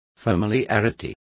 Complete with pronunciation of the translation of familiarity.